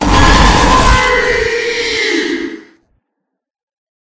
jumpscare.mp3